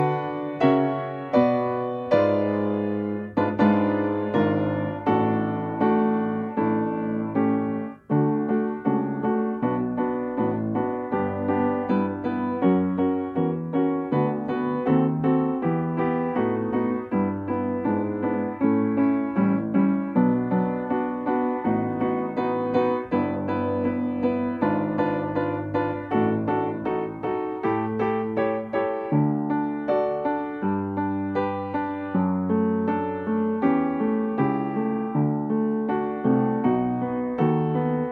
akompaniamentu pianina
Nagrane z metronomem.
I wersja: 80 bmp – Nagranie nie uwzględnia zwolnienia.
Nagranie dokonane na pianinie Yamaha P2, strój 440Hz
piano